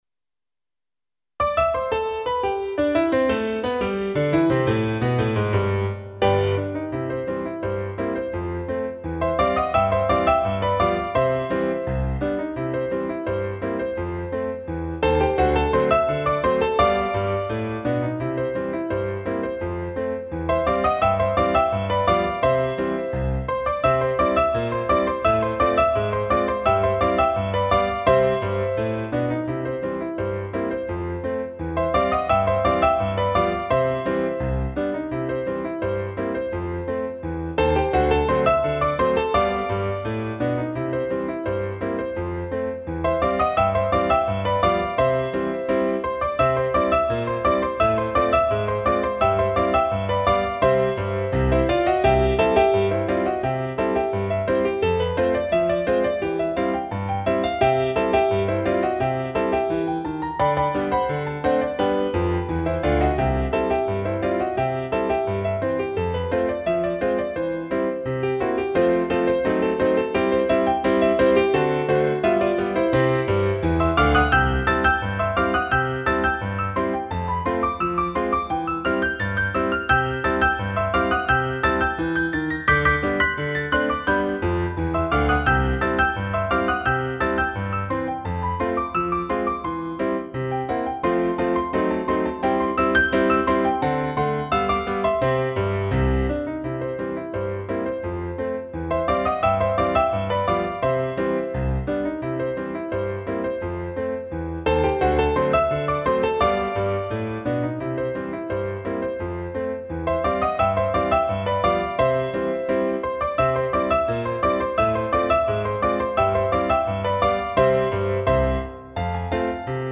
I Made it with "Miroslav Philharmonik"
RAGTIME MUSIC